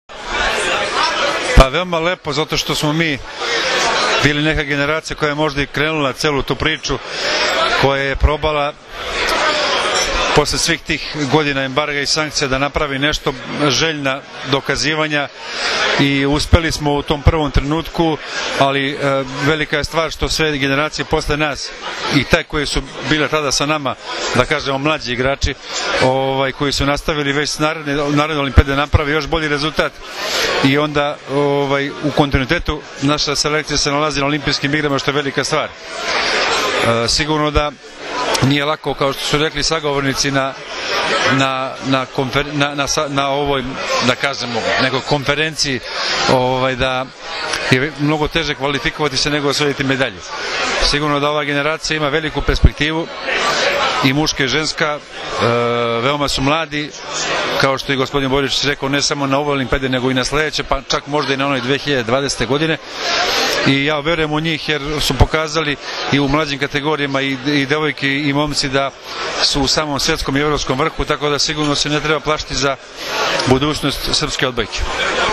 Odbojkaški savez Srbije je večeras u beogradskom hotelu „M“ priredio svečanost pod nazivom „Olimpijski kontinutitet“ povodom plasmana ženske i muške seniorske reprezentacije na Olimpijske igre u Londonu.
IZJAVA